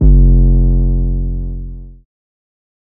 REDD 808 (14).wav